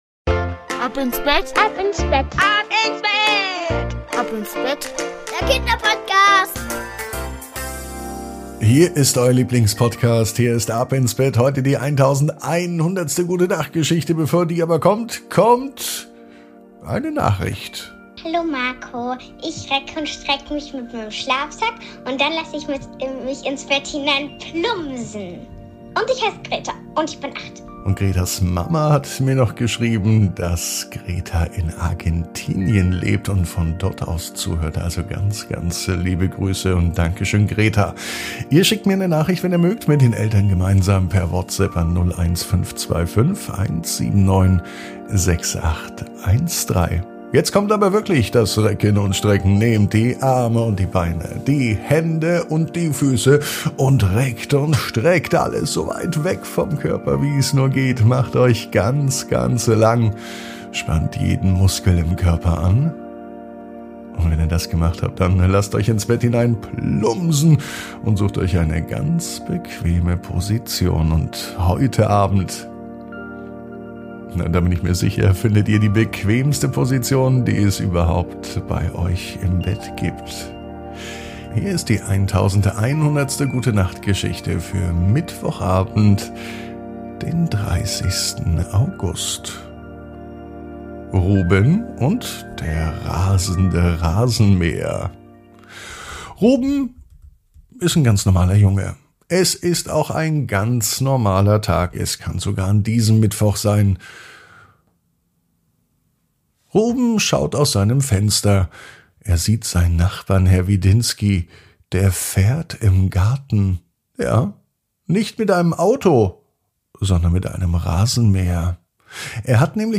Die Gute Nacht Geschichte für Mittwoch